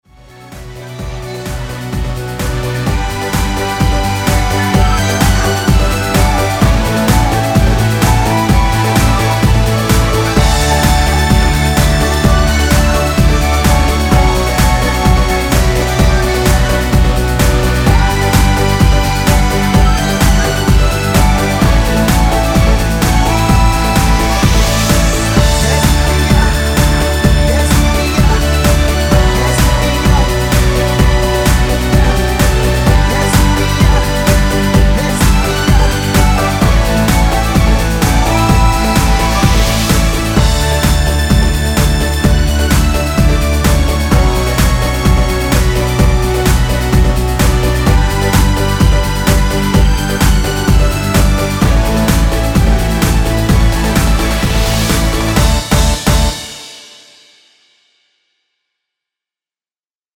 원키 멜로디와 코러스 포함된 MR 입니다.
엔딩이 페이드 아웃이라 엔딩을 만들어 놓았습니다.(미리듣기 확인)
Db
앞부분30초, 뒷부분30초씩 편집해서 올려 드리고 있습니다.
중간에 음이 끈어지고 다시 나오는 이유는